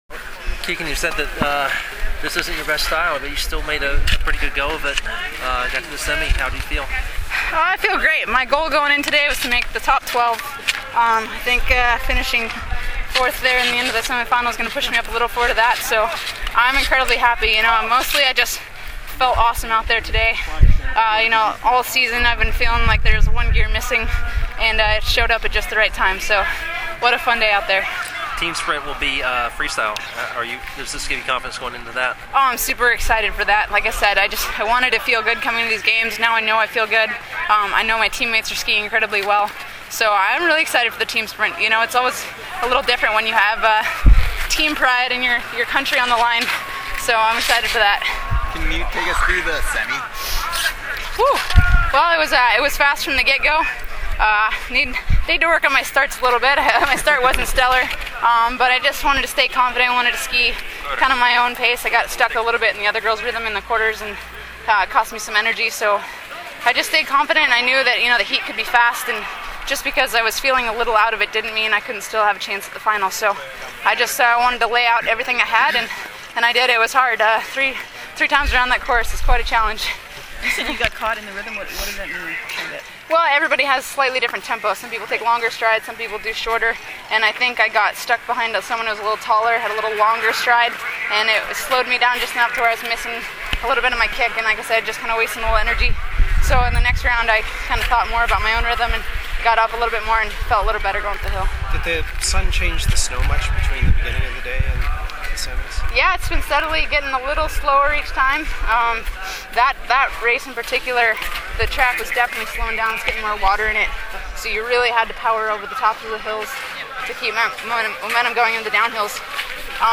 Audio:Post-race interviews with Kikkan Randall